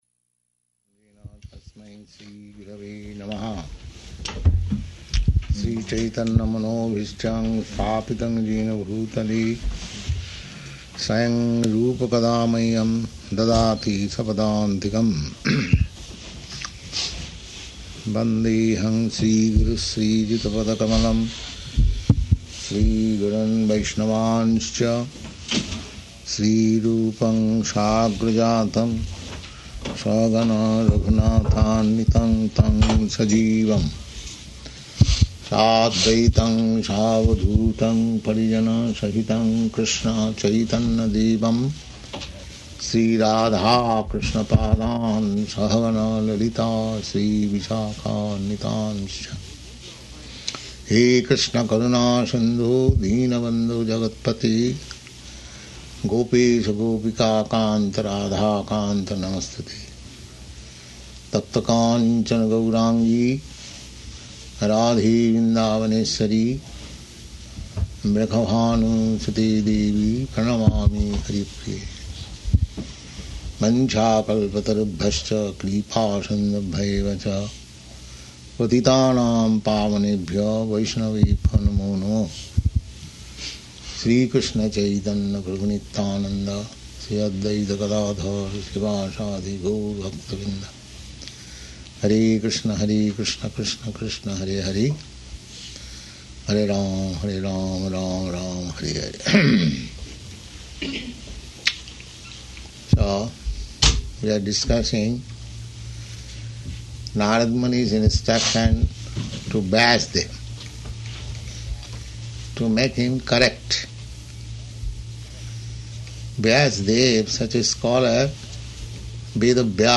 Location: New Vrindavan
Prabhupāda: [chants maṅgalācaraṇa ]